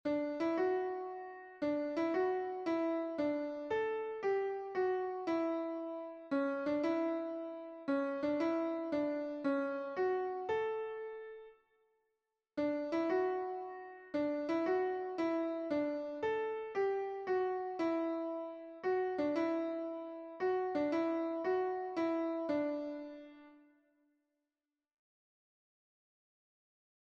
It's a lilting, marchy tune in D Major, 9/8ths time (although it feels more like 3/4 with some swing).